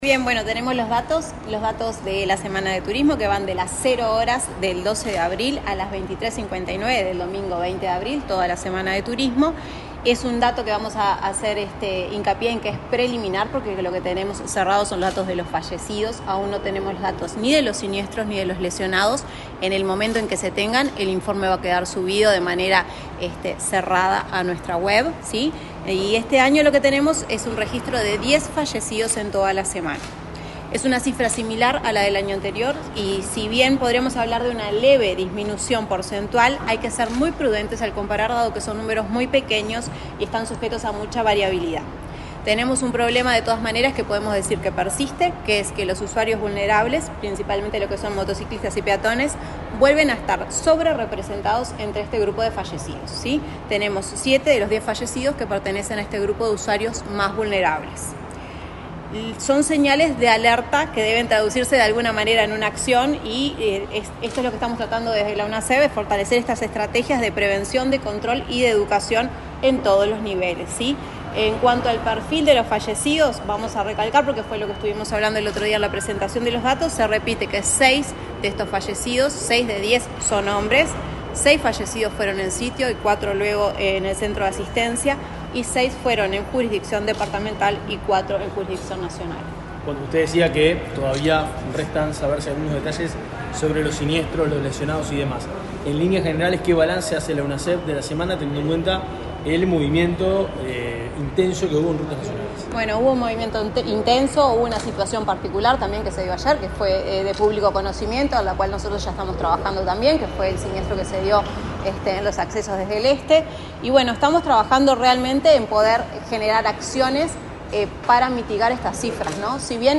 Declaraciones de la secretaria general ejecutiva de Unasev, María Fernanda Artagaveytia
Declaraciones de la secretaria general ejecutiva de Unasev, María Fernanda Artagaveytia 21/04/2025 Compartir Facebook X Copiar enlace WhatsApp LinkedIn La secretaria general ejecutiva de la Unidad Nacional de Seguridad Vial (Unasev), María Fernanda Artagaveytia, informó a la prensa en la Torre Ejecutiva, acerca de los datos de siniestralidad vial durante la Semana de Turismo.